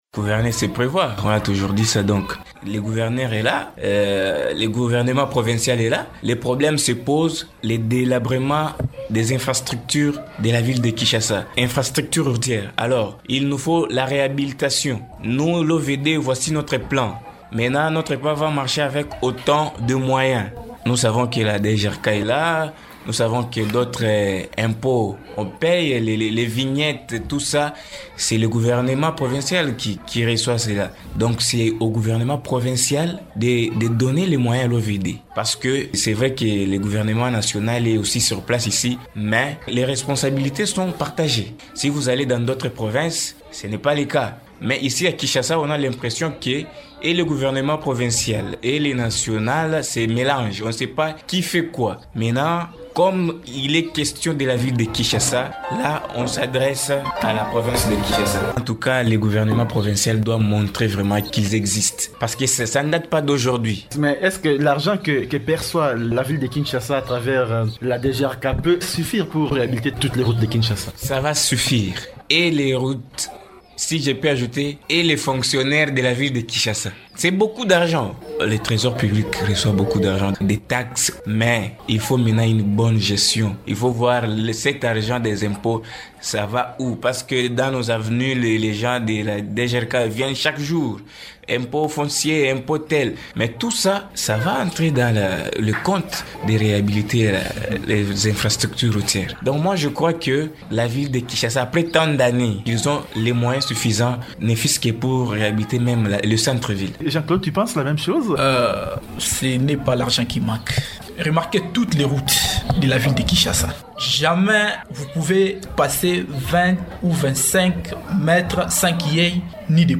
Radio Okapi a interrogé des jeunes habitants de la ville de Kinshasa sur les moyens pour réhabiliter les infrastructures routières dans la capitale congolaise.